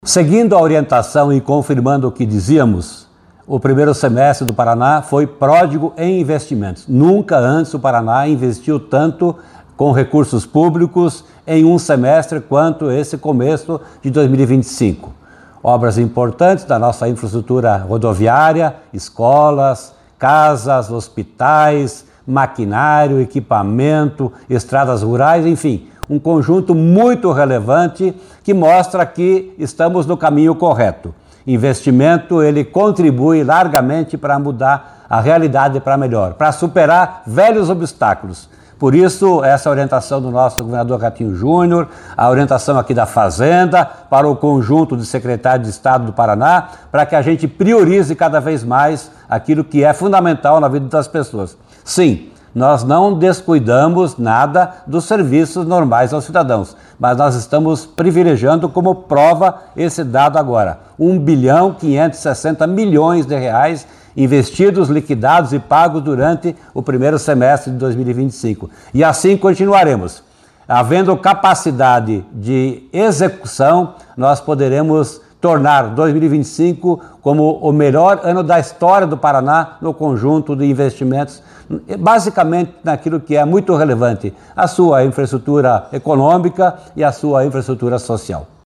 Sonora do secretário da Fazenda, Norberto Ortigara, sobre o recorde de investimentos no primeiro semestre de 2025 no Paraná